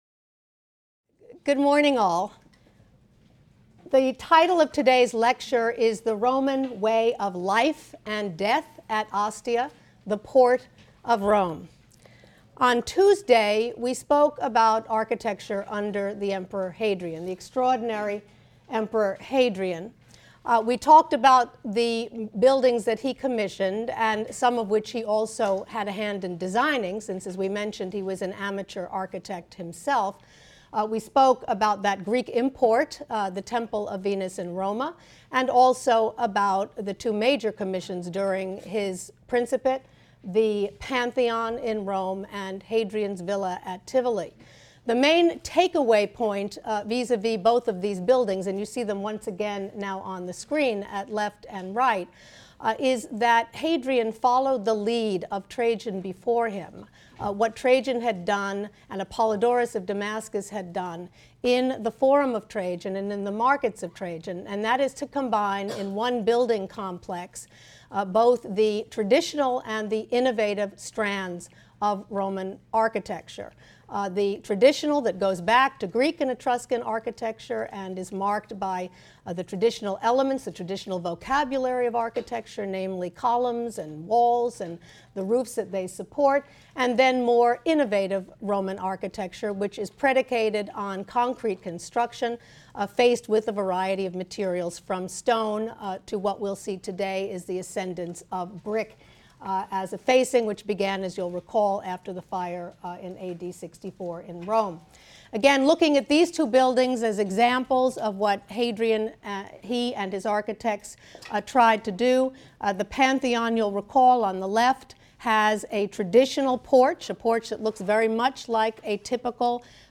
HSAR 252 - Lecture 16 - The Roman Way of Life and Death at Ostia, the Port of Rome | Open Yale Courses